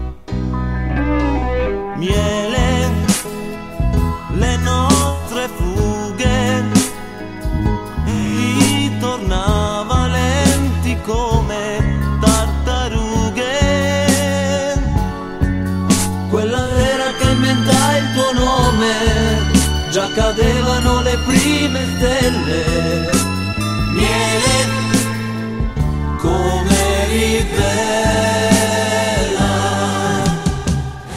высокие частоты просто забивают слух